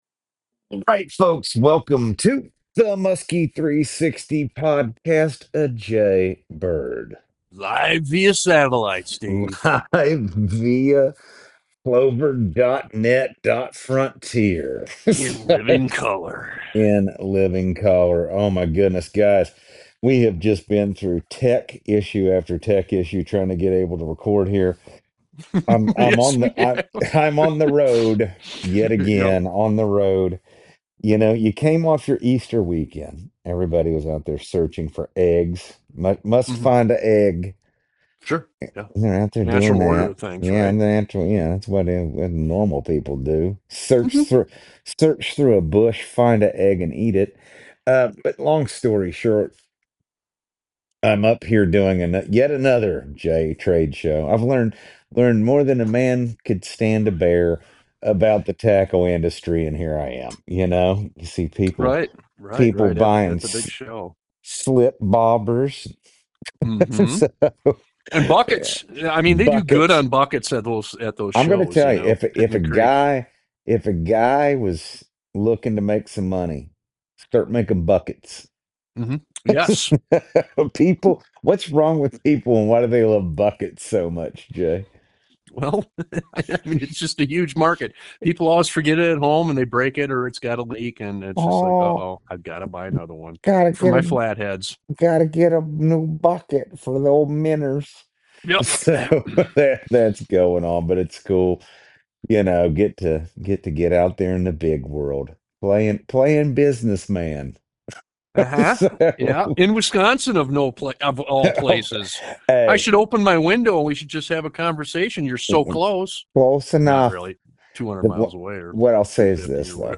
from the road on location with up to the minute Musky fishing news. Plus lots of Q&A with breaking down baitfish locations and how to catch muskies under changing conditions.